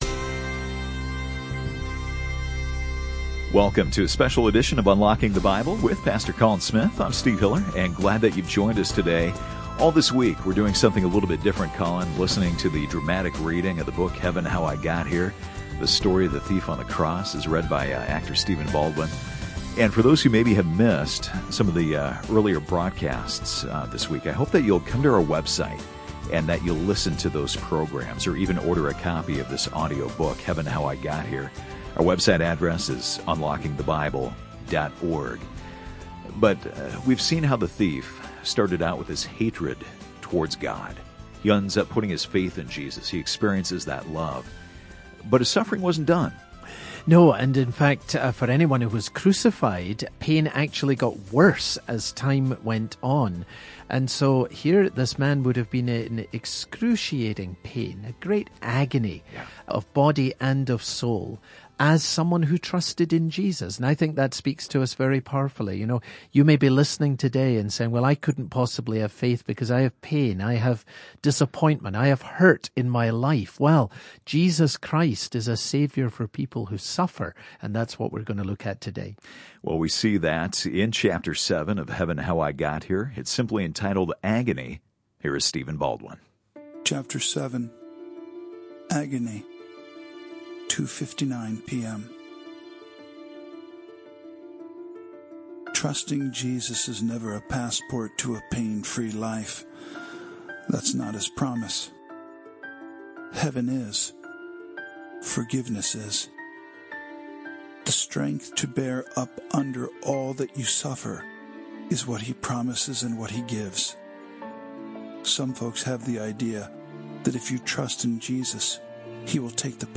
Heaven, How I Got Here is his story, told in his own words, as he looks back from Heaven on the day that changed his eternity, and the faith that can change yours. This radio broadcast features narration by actor Stephen Baldwin.